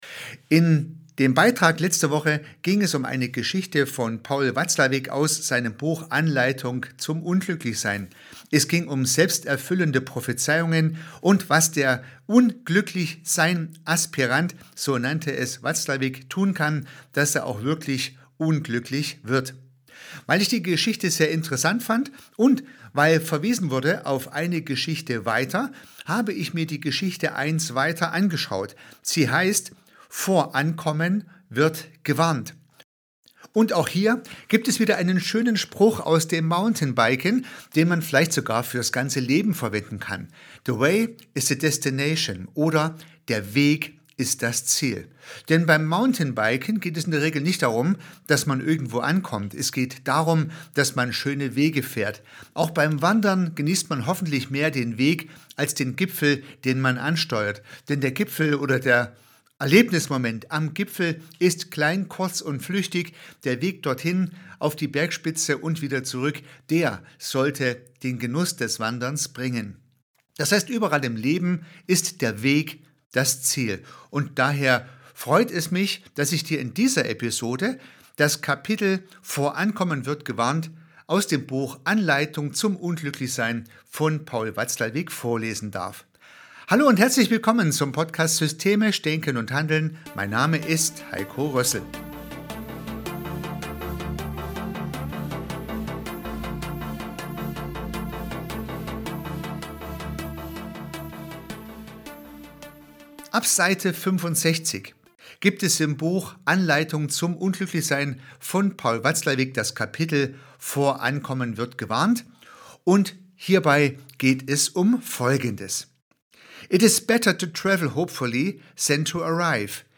Ich lese teile des Kapitels